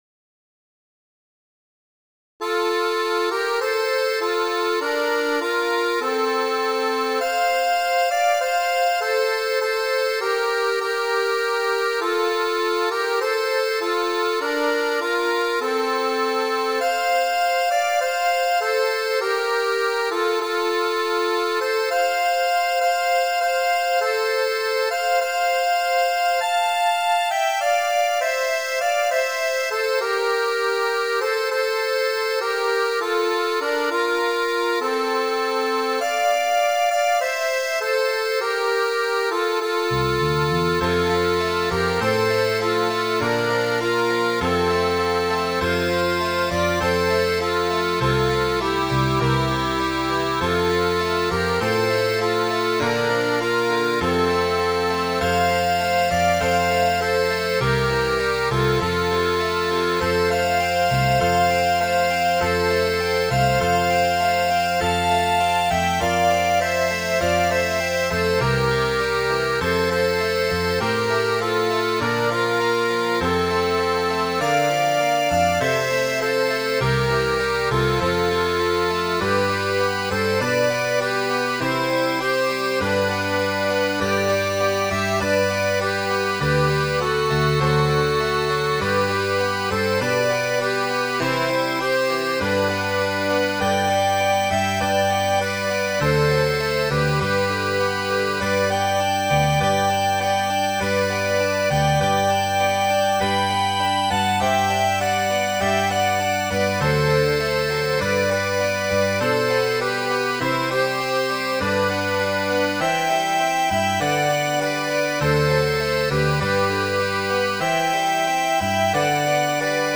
willyeno.mid.ogg